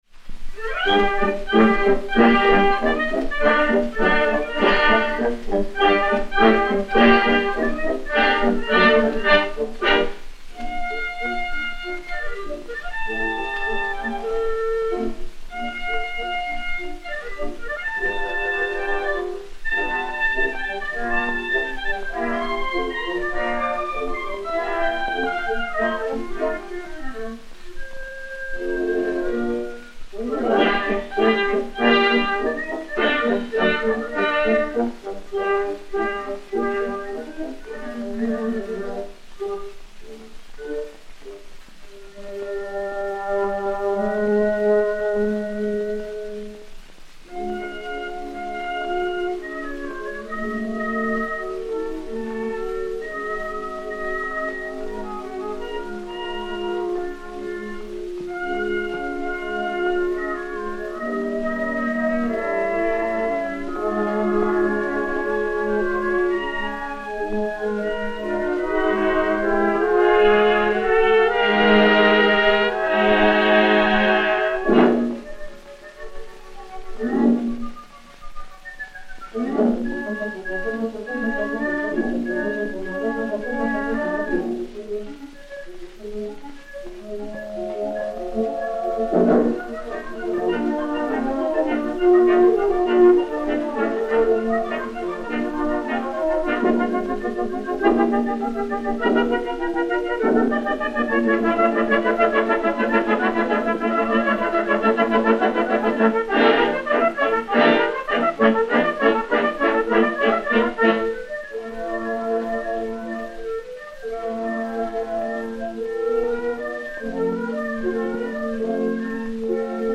Orchestre dir.
Pathé saphir 80 tours n° 6162, matrice 7148, enr. à Paris vers 1912